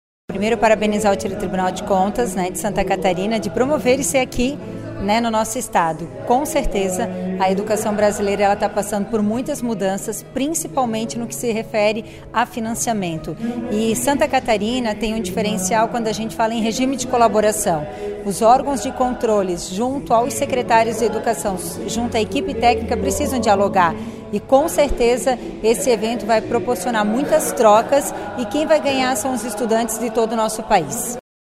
IV Sined e III Encontro de Promotores e Promotoras de Justiça da Educação - áudios dos participantes
Solenidade de abertura